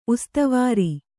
♪ ustavāri